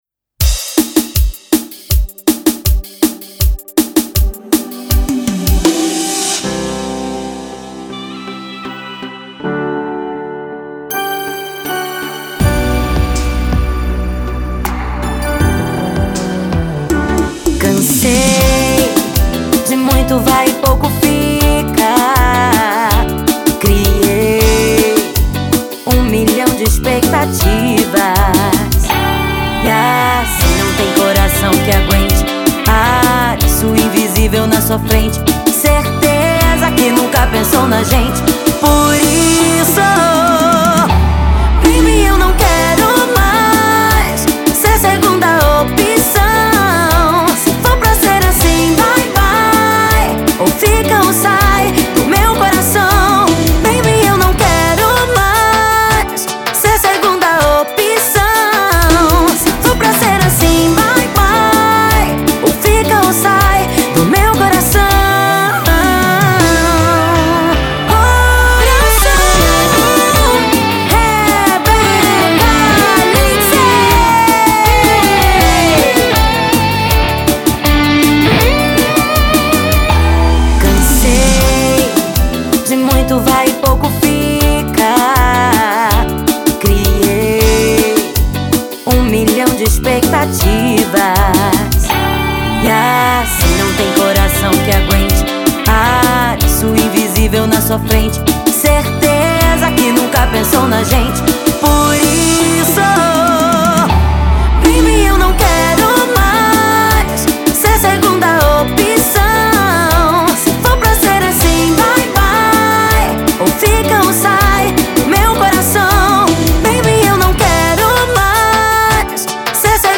Labels: Melody